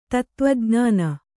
♪ tattvajṇāna